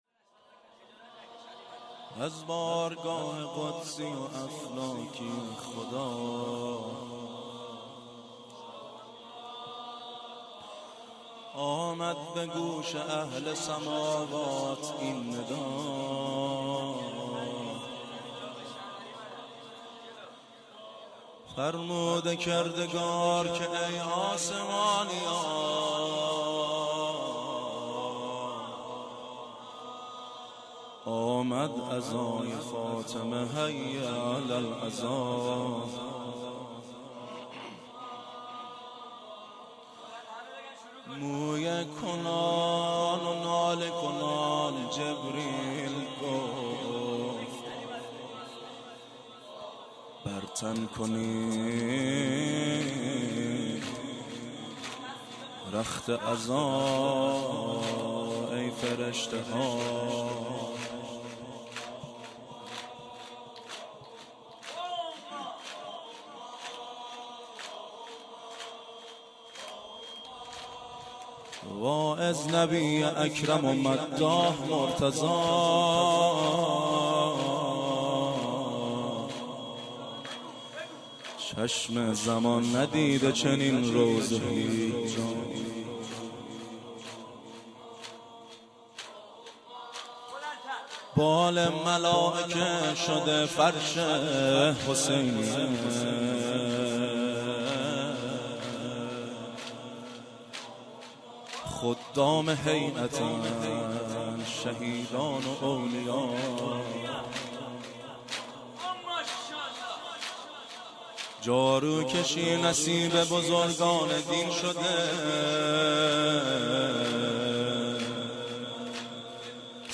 شب دوم ویژه برنامه فاطمیه دوم ۱۴۳۹